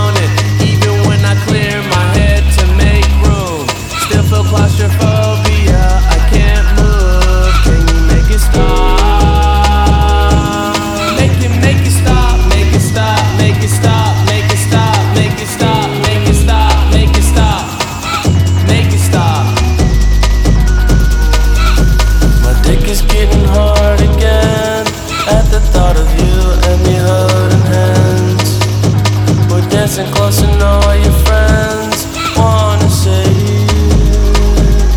Жанр: R&B / Альтернатива / Соул